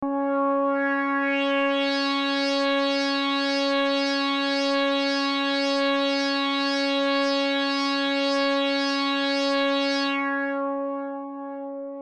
标签： MIDI-速度-100 CSharp5 MIDI音符-73 ELEKTRON-模拟四 合成器 单 - 注意 多重采样
声道立体声